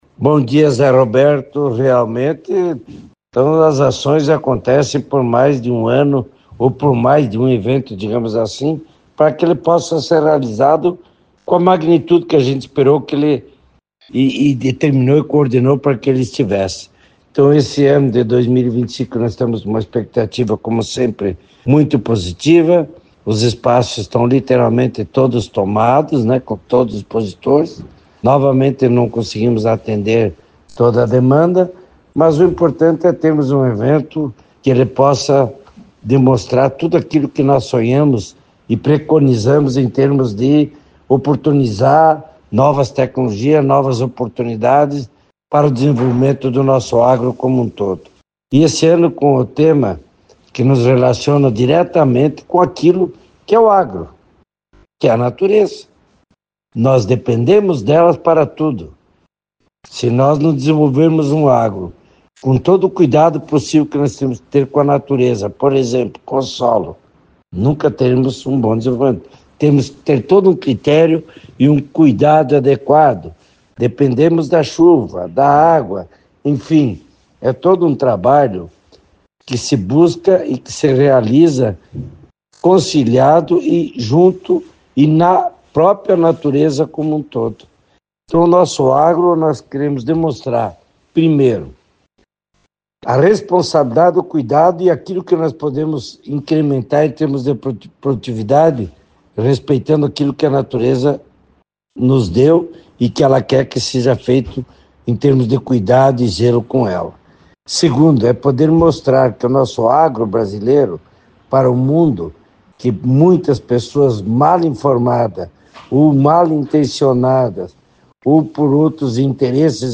O Show Rural 2025 será realizado de 10 a 14 de fevereiro. Em entrevista à CBN cascavel nesta quarta-feira (11)